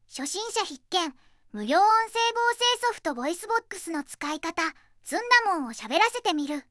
VOICEVOXとは、2021年8月1日からネット上に公開されている、テキストを音声に変換するためのソフトウェアです。
実際にずんだもんにしゃべらせてみた言葉が、こちらです。
すごくなめらかに、話せていますね！
001_ずんだもん（ノーマル）_【初心者必見】無料….wav